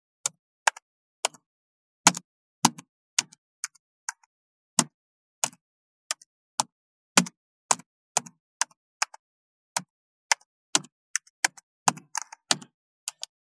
35.タイピング【無料効果音】
ASMRタイピング効果音
ASMR